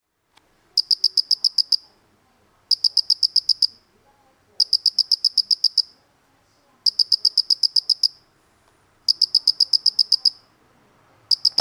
mitukadokoorogi.MP3